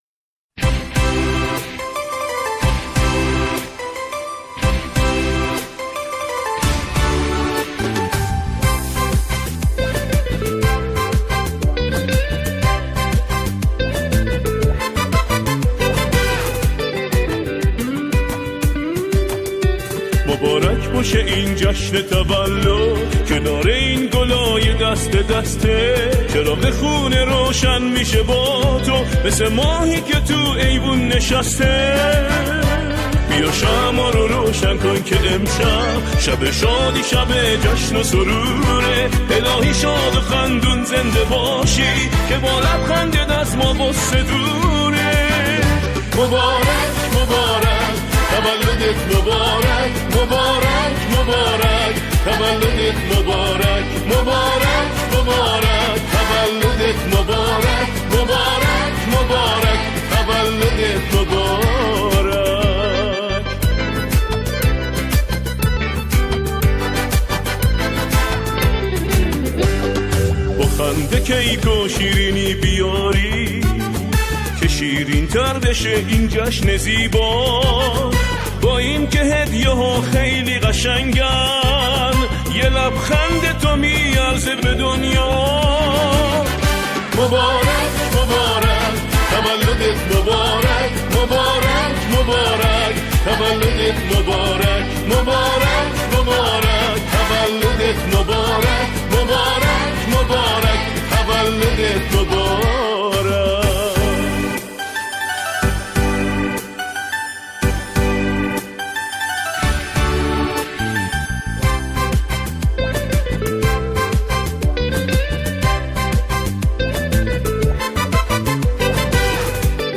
گروهی از همخوانان اجرا می‌کنند.